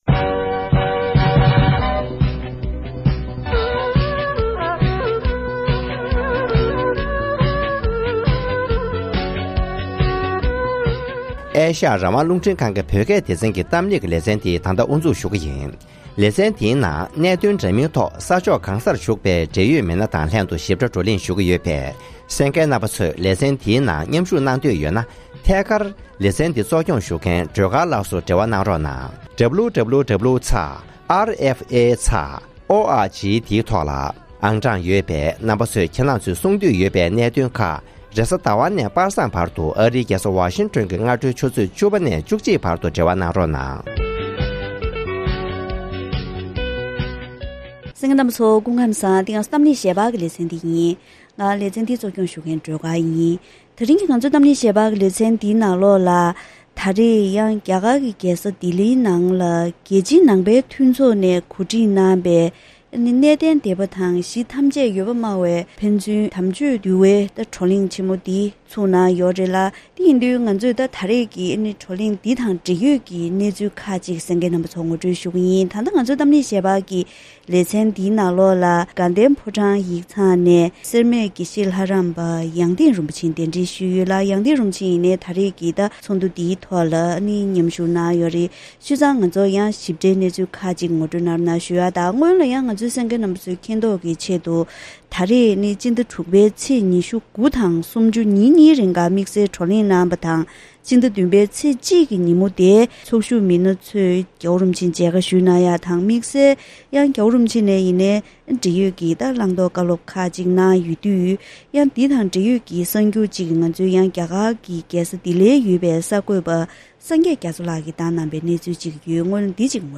གཏམ་གླེང་ཞལ་པར་ལེ་ཚན་ནང་ཉེ་ཆར་རྒྱ་གར་གྱི་རྒྱལ་ས་ལྡི་ལིར་གནས་བརྟན་སྡེ་པ་དང་གཞི་ཐམས་ཅད་ཡོད་པ་སྨྲ་བའི་བར་དམ་ཆོས་འདུལ་བ་བགྲོ་གླེང་ཞིག་གནང་ཡོད་པས། འདུལ་བའི་ཉམས་བཞེས་གནང་ཕྱོགས་ཐད་མི་འདྲ་བ་དང་ཐུན་མོང་གི་གནས་སྟངས་ཇི་འདྲ་ཡོད་མིན་དང་། བགྲོ་གླེང་དེ་རིགས་གནང་བརྒྱུད་དགེ་མཚན་གང་ལྡན་ཡོད་མིན་སོགས་ཀྱི་སྐོར་ལ་འབྲེལ་ཡོད་དང་ལྷན་དུ་བཀའ་མོལ་ཞུས་པ་ཞིག་གསན་རོགས་གནང་།